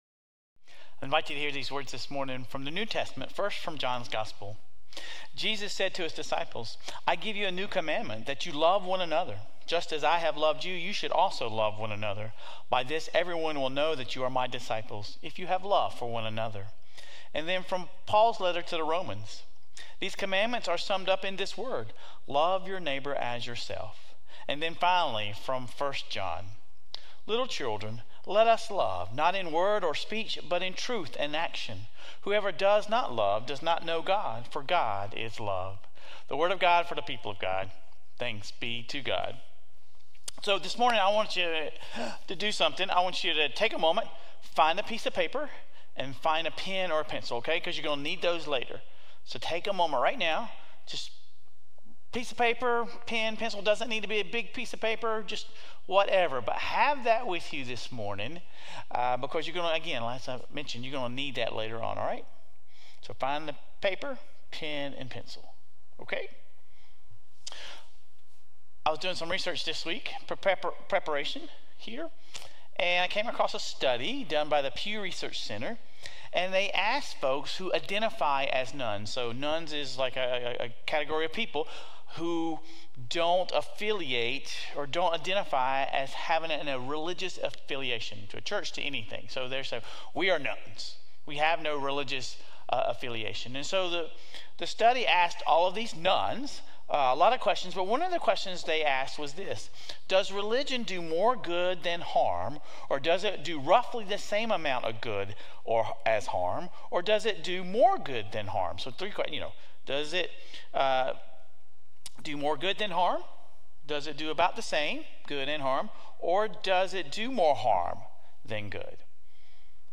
Sermon Resources Prayer of St. Francis of Assisi and phone wallpapers Sermon Reflections How are you challenged to move beyond words and feelings and into concrete actions that demonstrate love for your neighbor?